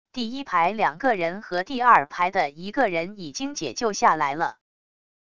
第一排两个人和第二排的一个人已经解救下来了wav音频生成系统WAV Audio Player